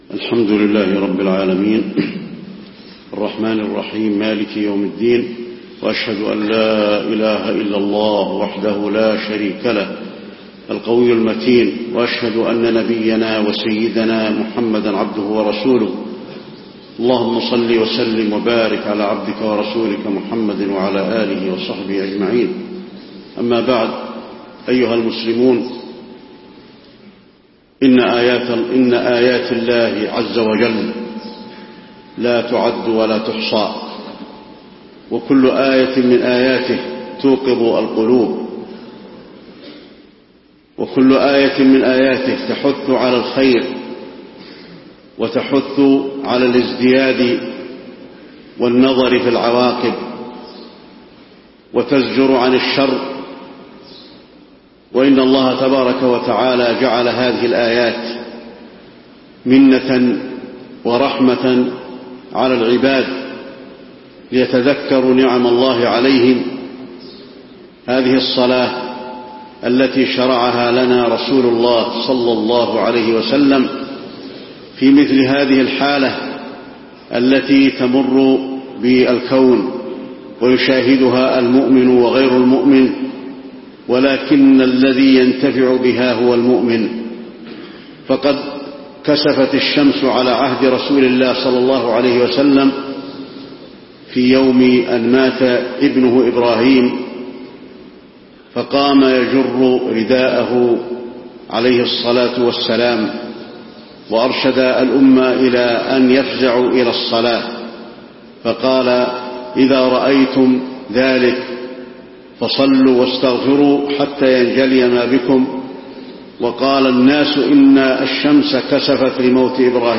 خطبة الكسوف المدينة - الشيخ علي الحذيفي
تاريخ النشر ٢٩ محرم ١٤٣٢ هـ المكان: المسجد النبوي الشيخ: فضيلة الشيخ د. علي بن عبدالرحمن الحذيفي فضيلة الشيخ د. علي بن عبدالرحمن الحذيفي خطبة الكسوف المدينة - الشيخ علي الحذيفي